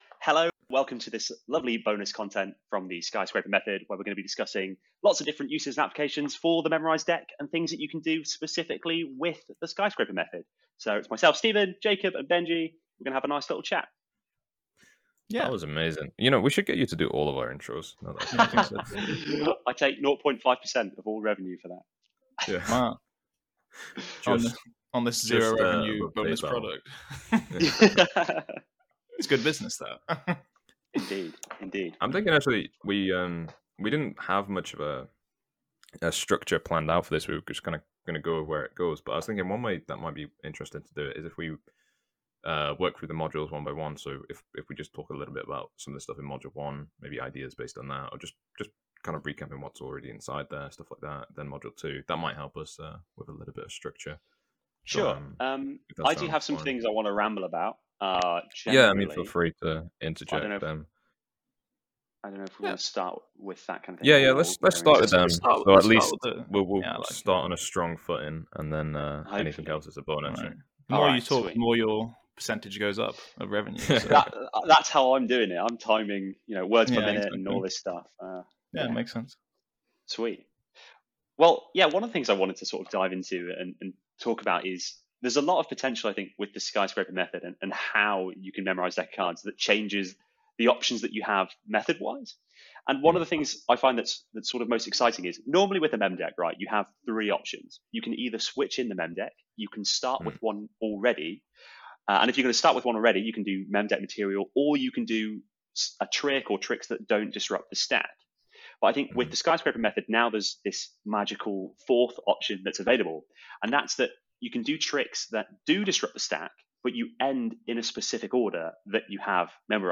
So we decided to jump on a call and brainstorm with him…